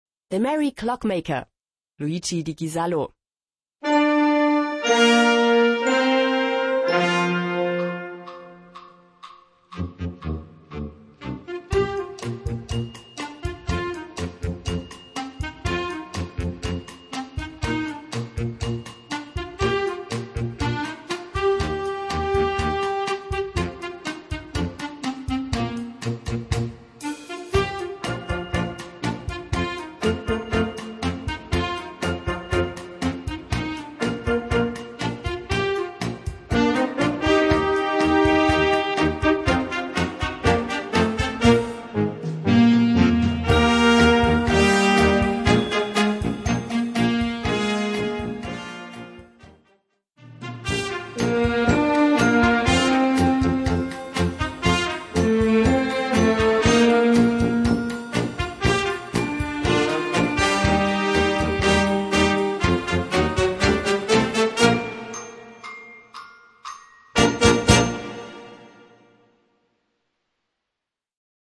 Gattung: Solo für Mallets
Besetzung: Blasorchester
ist als Solo für Glockenspiel geschrieben.